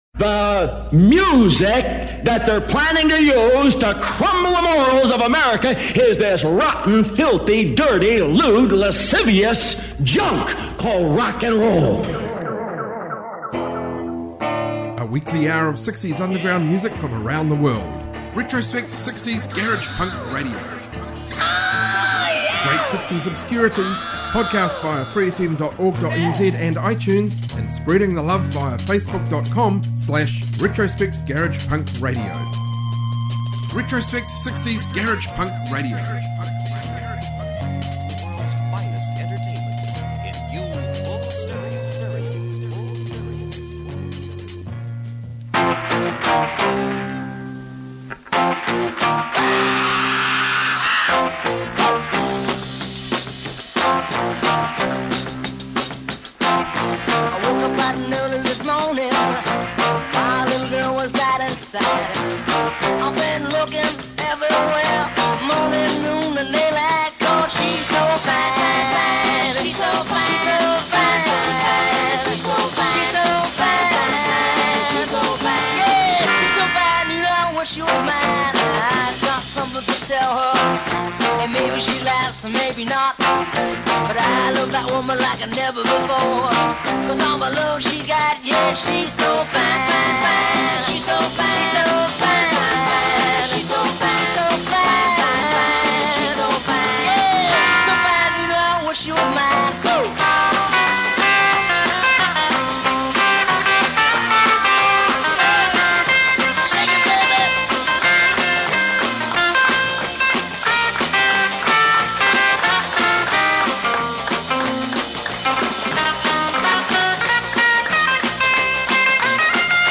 60s garage punk, garage rock, freakbeat from around the globe